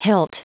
Prononciation du mot hilt en anglais (fichier audio)
Prononciation du mot : hilt